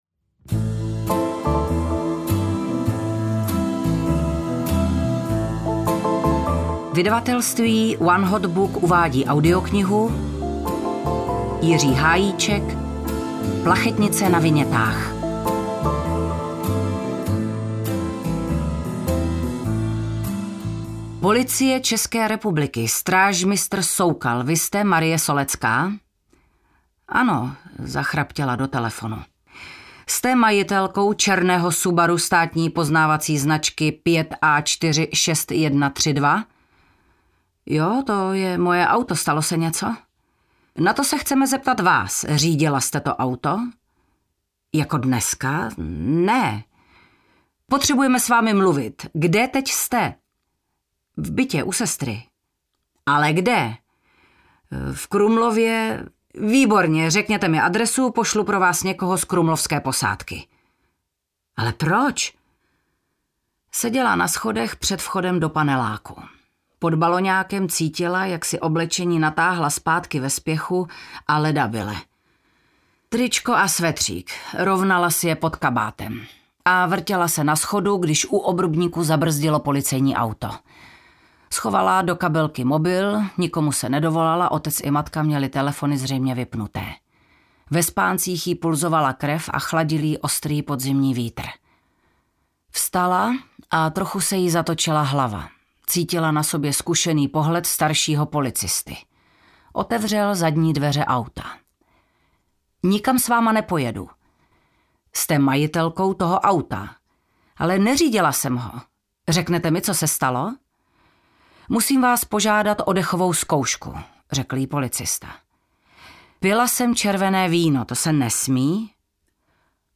Plachetnice na vinětách audiokniha
Ukázka z knihy